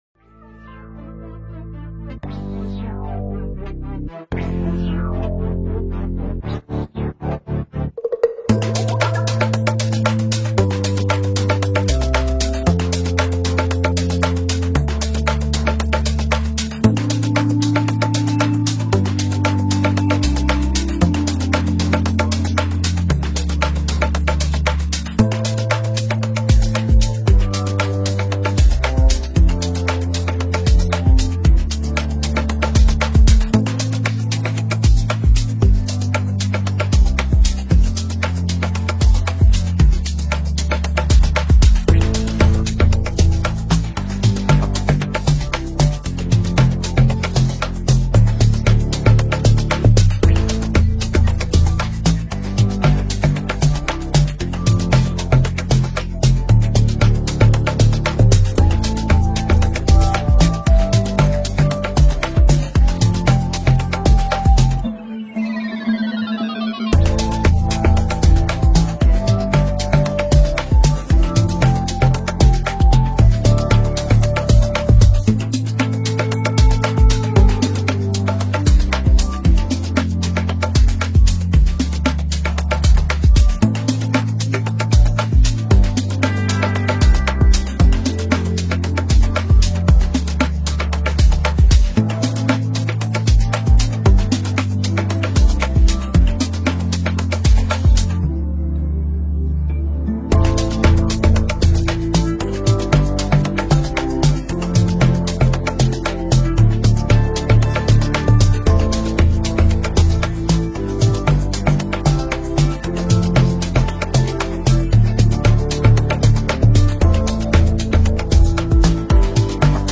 Stream, Listen to and Download this instrumental beat below.